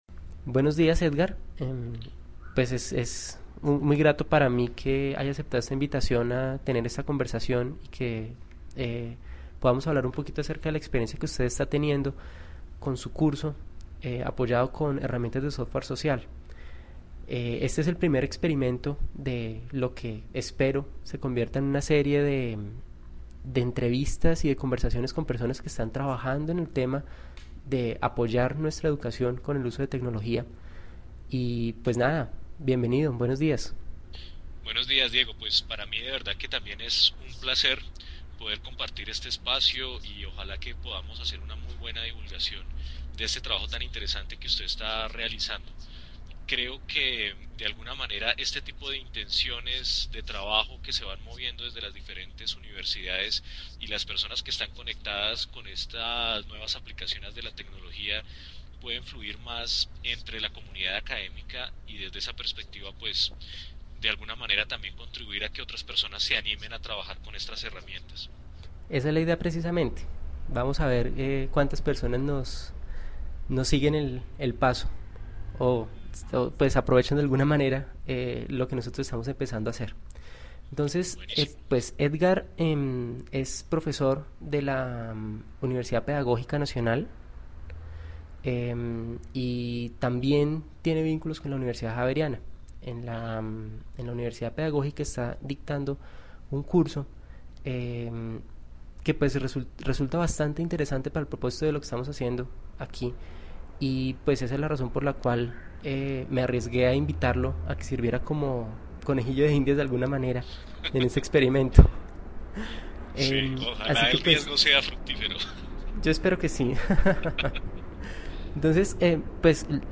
La intención del ejercicio fue probar qué tan difícil era grabar una entrevista de Skype y organizar un podcast con el producto.